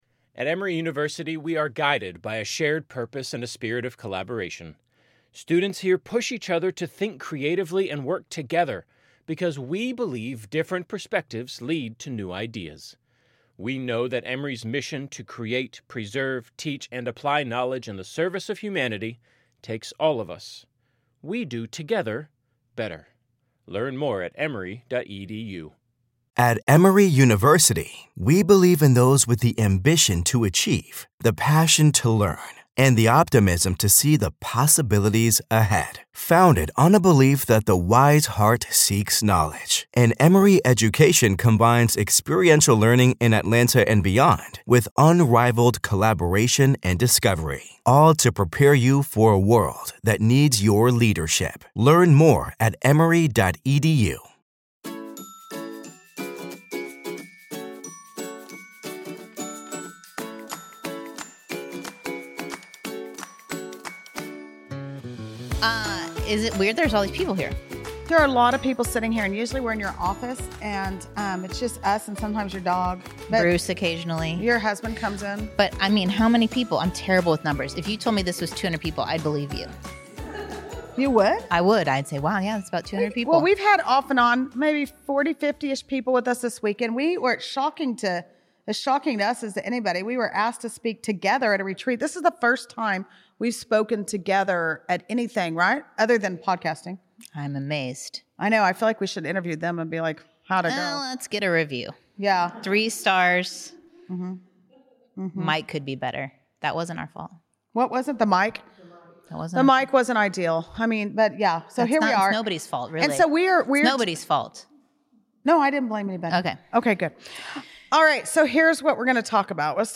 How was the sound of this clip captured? Sidetracked LIVE (what could possibly go wrong, amiright?)